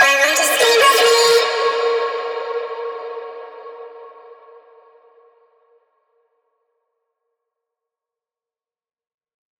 VR_vox_hit_staywithme_D#.wav